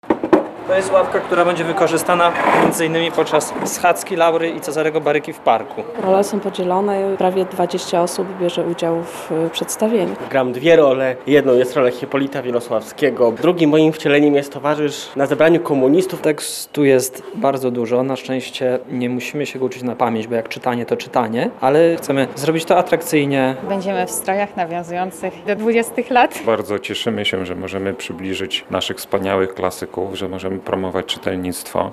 Nie przeszkadzają im uliczny gwar, remont sąsiedniego budynku czy pochmurna pogoda. Pracownicy Wojewódzkiej Biblioteki Publicznej im. Hieronima Łopacińskiego w Lublinie ćwiczą inscenizację fragmentów „Przedwiośnia” Stefana Żeromskiego. W sobotę – 8 września – podczas ogólnopolskiej akcji Narodowego Czytania tej powieści zaproponują swoją interpretację.
bibliotekarze_jako_aktorzy.mp3